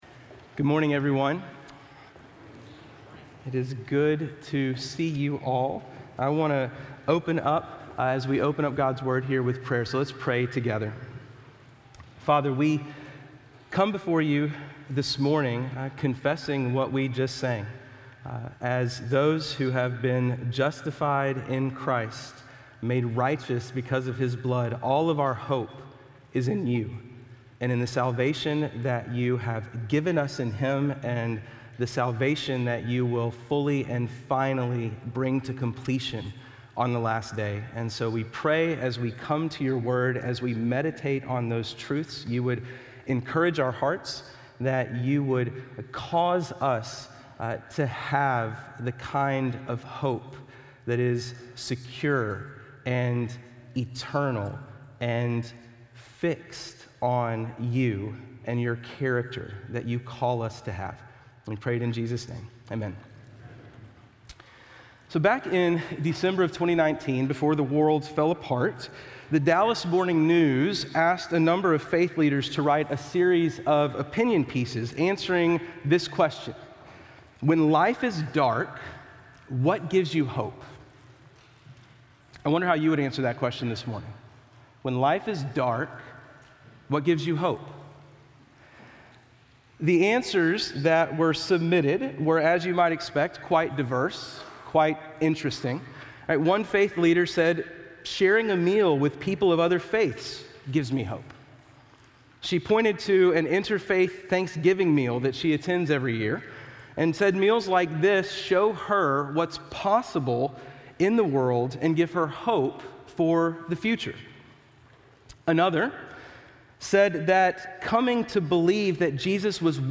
Video & Sermon Audio  Continue reading